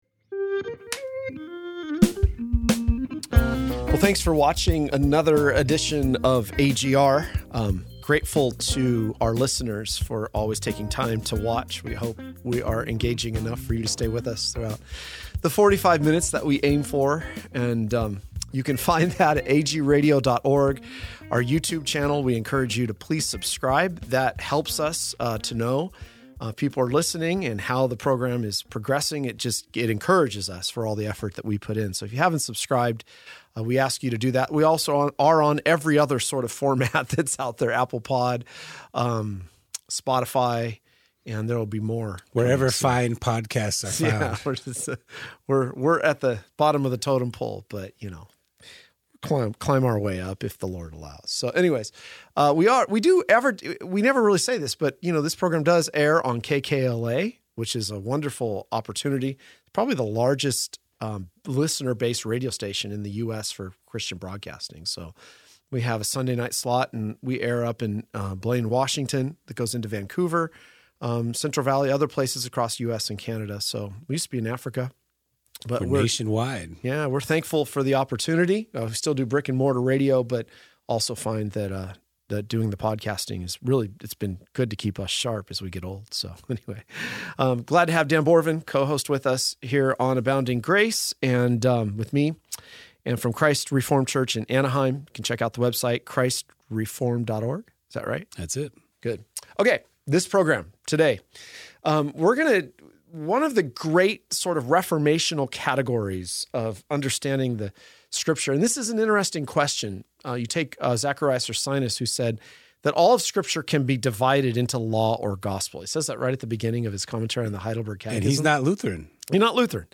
This discussion explores the interplay of law and gospel, the three uses of the law in reformed theology, and the surprising challenge Jesus poses to a seemingly righteous man.